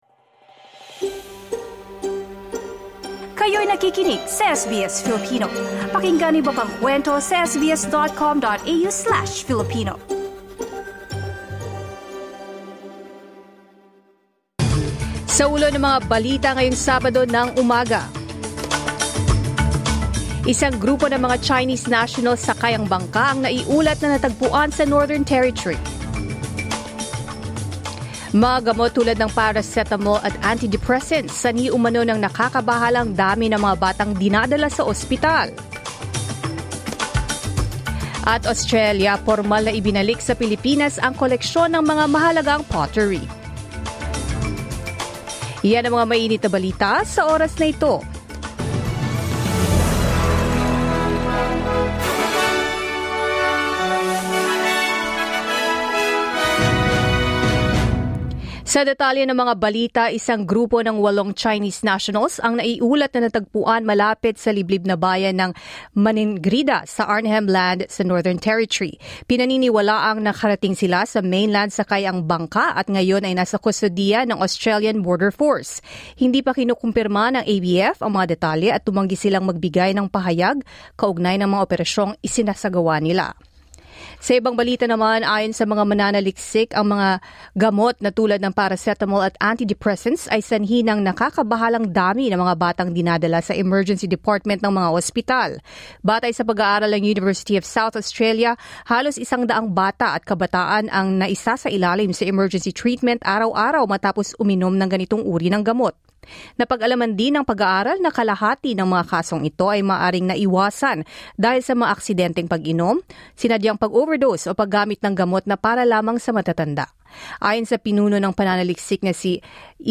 SBS News in Filipino, Saturday 31 May 2025